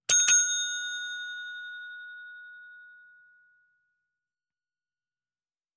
Звуки настольного звонка
Звук вызова клиента на обслуживание